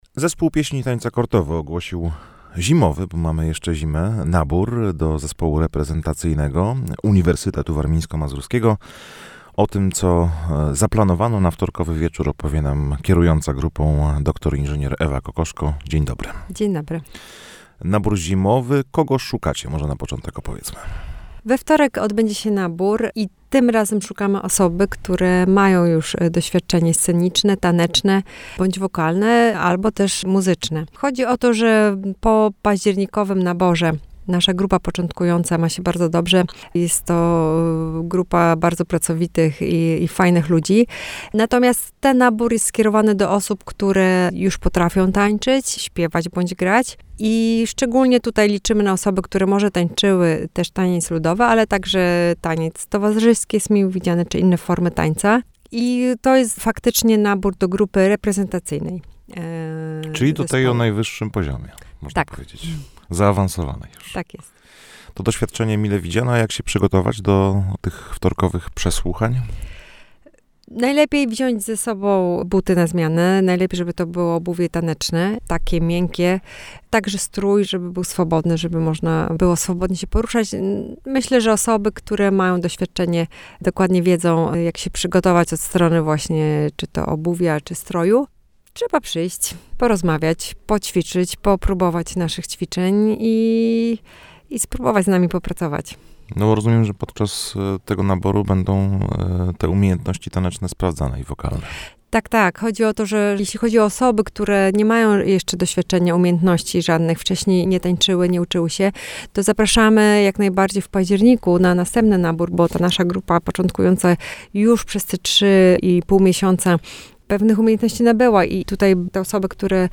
Na nabór grupa zaprasza także muzyków do kapeli.